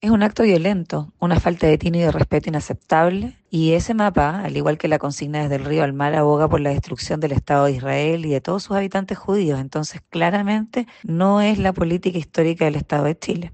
En conversación con La Radio